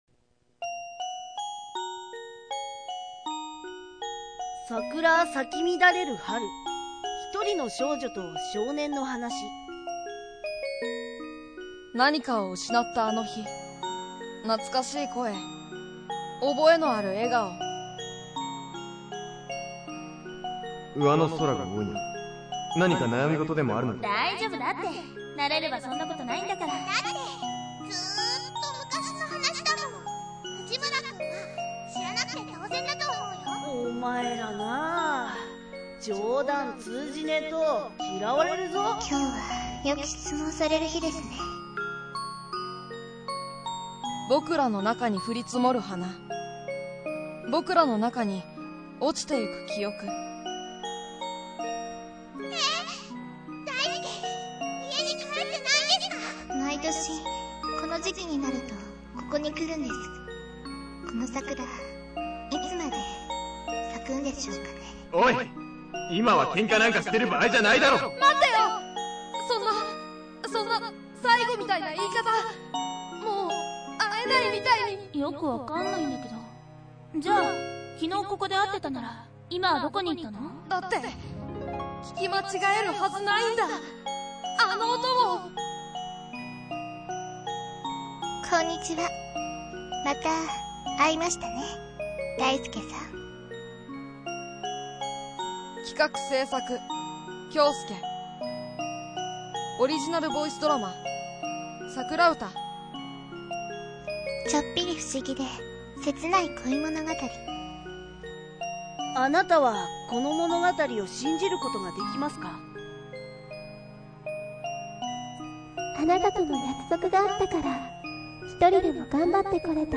ボイスドラマ